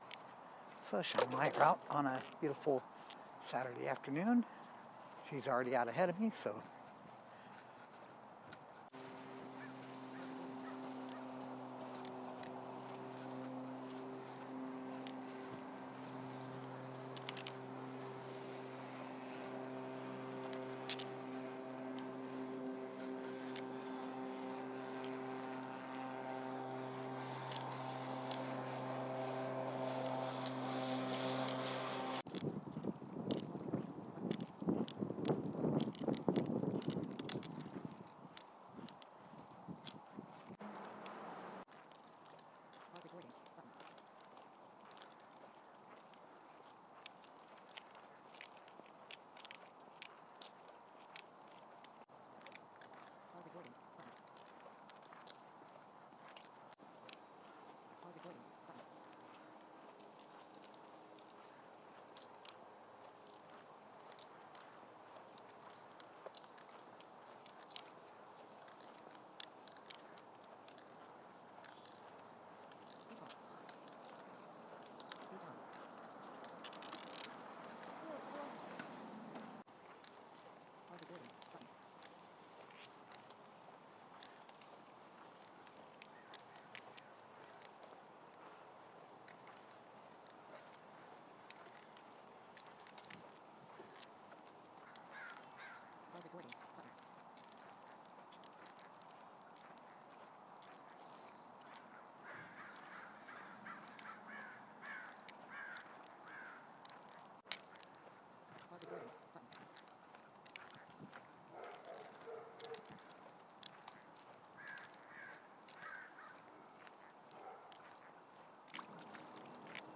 neighborhood sound